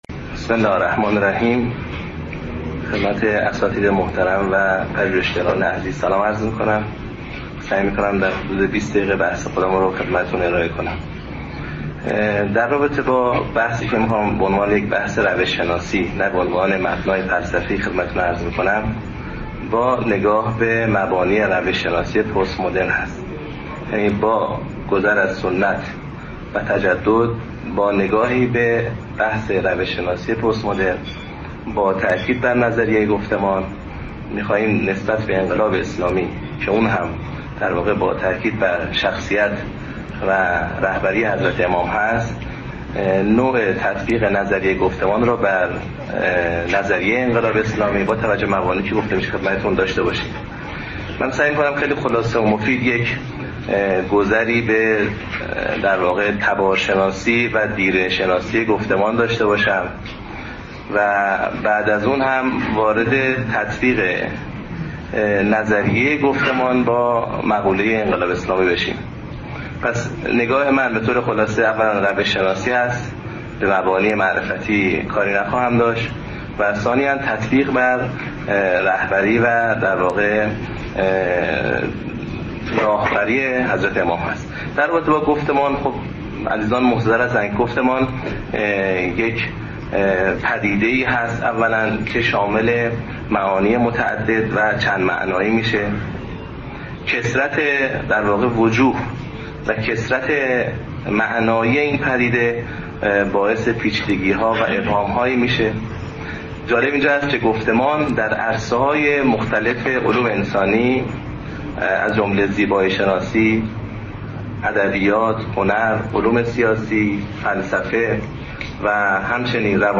سخنرانی
در همایش انقلاب اسلامی و نظریه پردازی در پژوهشگاه فرهنگ و اندیشه اسلامی